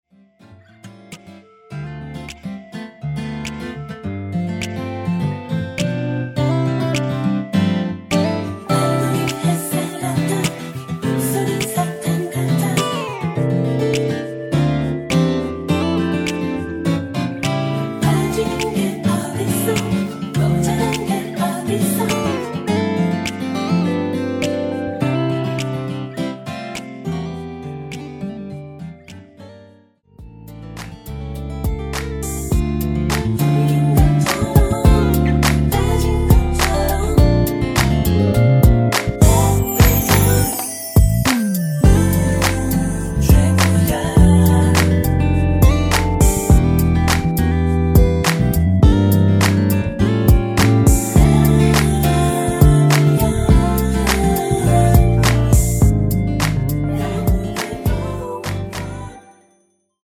(-2) 내린 멜로디 라인과 코러스가 포함된 MR 입니다.(미리듣기 참조)
앞부분30초, 뒷부분30초씩 편집해서 올려 드리고 있습니다.
중간에 음이 끈어지고 다시 나오는 이유는